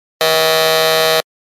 Звуки неправильного ответа
На этой странице собрана коллекция звуковых эффектов, обозначающих неправильный ответ или неудачу.
Когда неправильно ответил